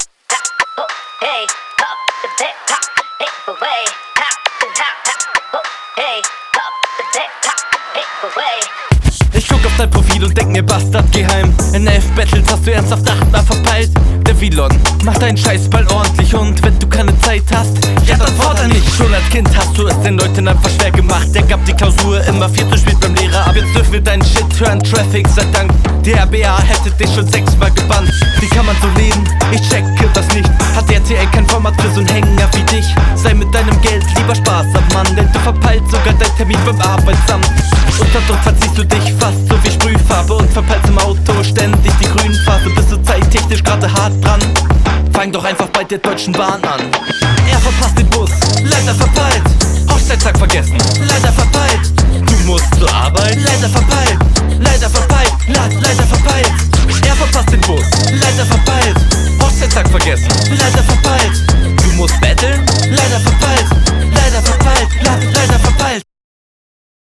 Kommst noch minimal unroutiniert.